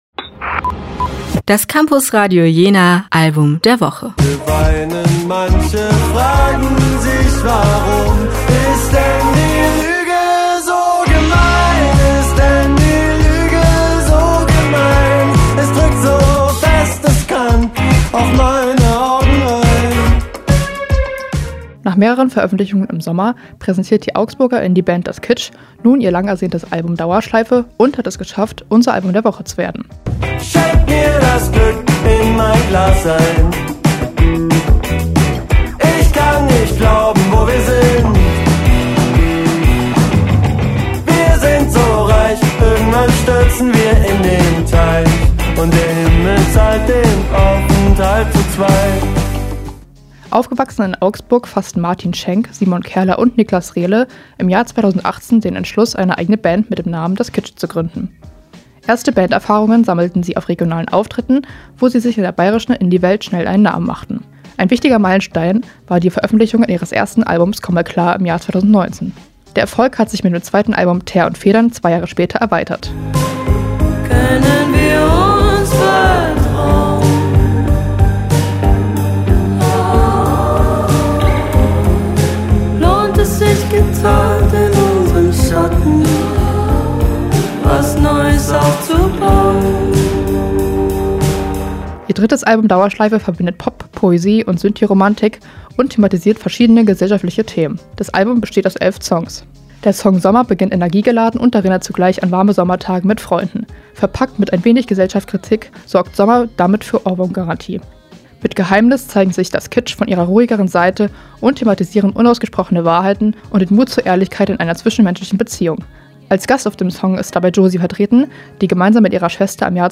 Nach mehreren Veröffentlichungen im Sommer, präsentiert die Augsburger Indie Band „Das Kitsch“ nun ihr langersehntes Album „Dauerschleife“ und hat es in unsere Vorstellung des Albums der Woche geschafft!
Ihr drittes Album „Dauerschleife“ verbindet Pop, Poesie und Synthie-Romantik und thematisiert verschiedene gesellschaftliche Themen.